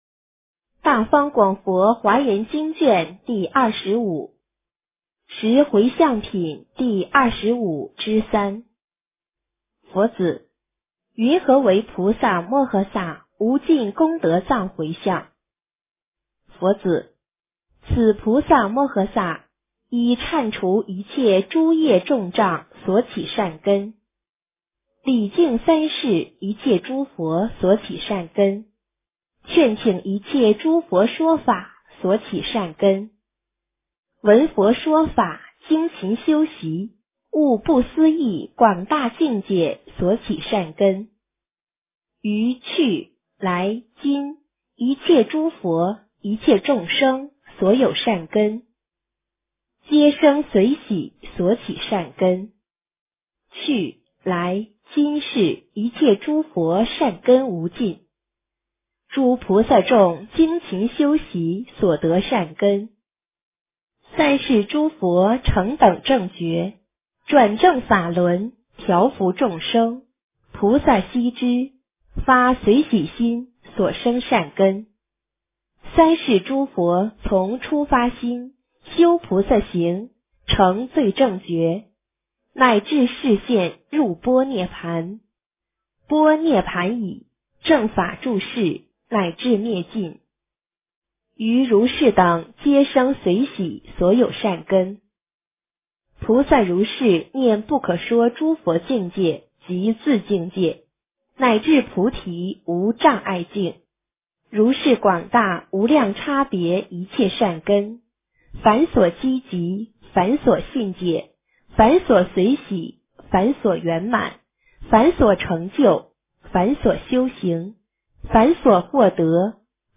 华严经25 - 诵经 - 云佛论坛